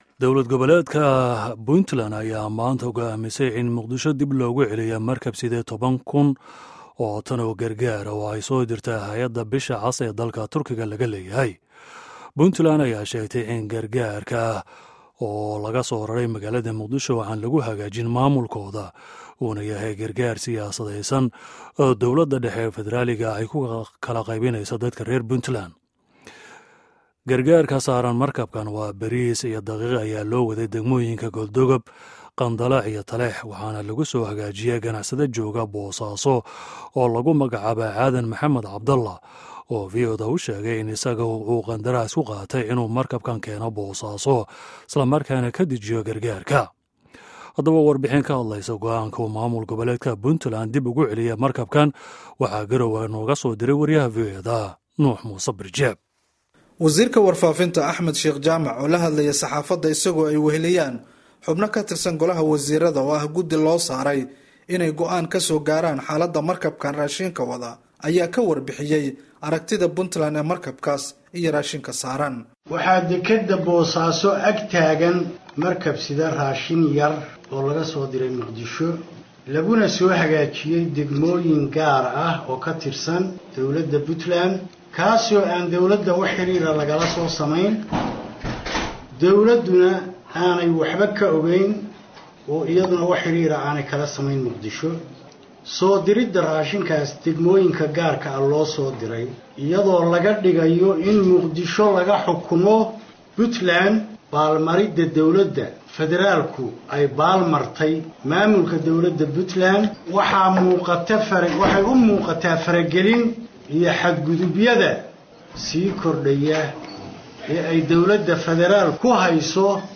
Dhageyso warbixinta go'aanka iyo wareysiga Cadde Muse